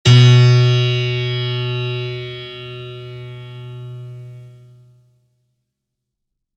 piano-sounds-dev
HardAndToughPiano